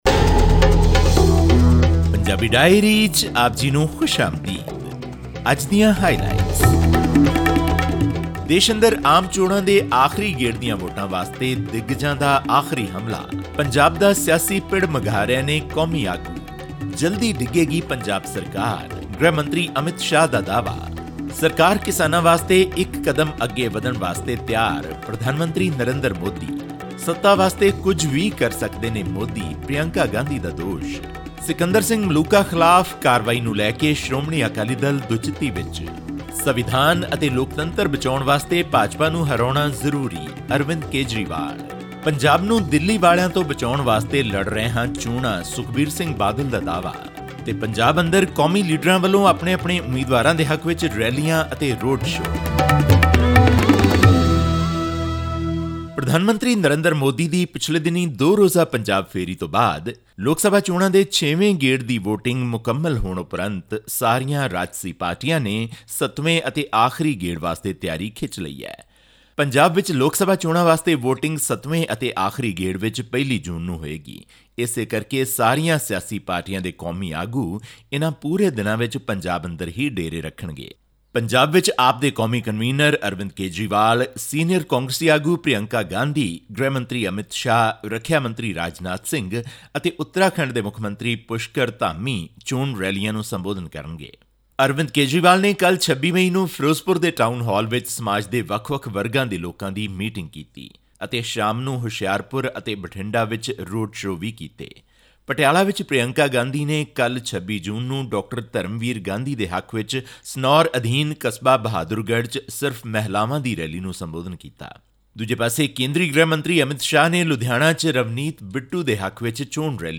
ਯਾਦ ਰਹੇ ਕਿ ਲੋਕ ਸਭਾ ਚੋਣਾਂ ਦੇ ਆਖਰੀ ਅਤੇ ਸੱਤਵੇਂ ਗੇੜ ਤਹਿਤ 1 ਜੂਨ ਨੂੰ ਵੋਟਾਂ ਪੈਣਗੀਆਂ ਅਤੇ 4 ਜੂਨ ਨੂੰ ਵੋਟਾਂ ਦੇ ਨਤੀਜੇ ਆਉਣਗੇ। ਹੋਰ ਵੇਰਵੇ ਲਈ ਸੁਣੋ ਇਹ ਆਡੀਓ ਰਿਪੋਰਟ...